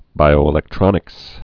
(bīō-ĭ-lĕk-trŏnĭks, -ē-lĕk-)